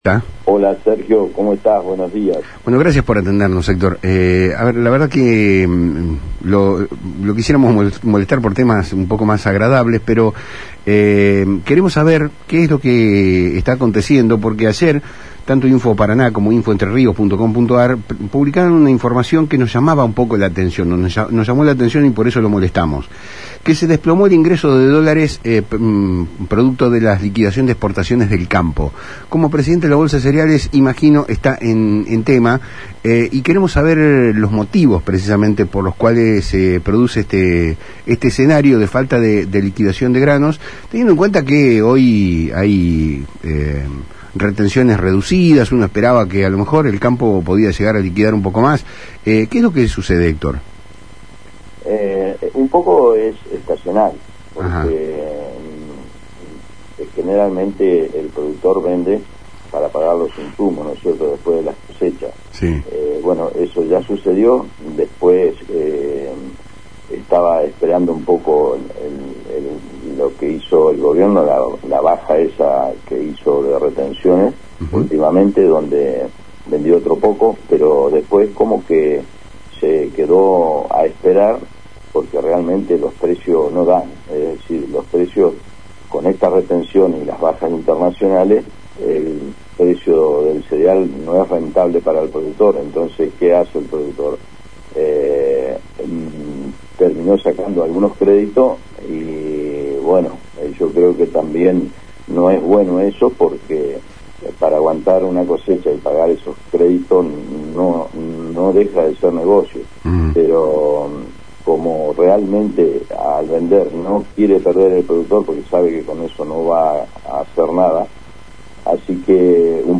En un diálogo con el programa Palabras Cruzadas de FM Litoral